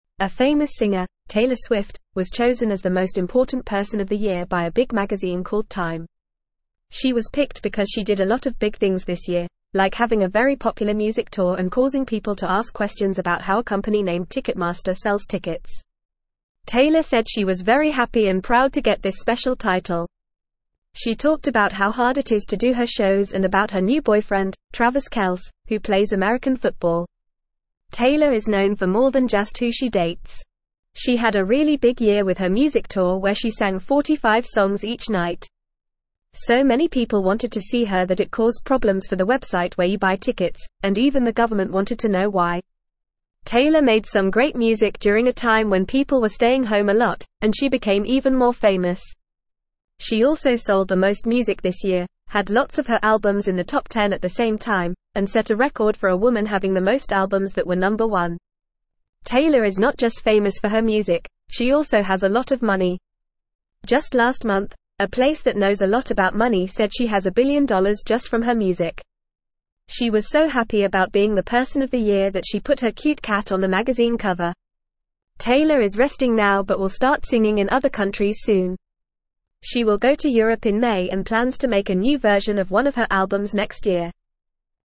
Fast
English-Newsroom-Lower-Intermediate-FAST-Reading-Taylor-Swift-The-Most-Important-Singer-of-the-Year.mp3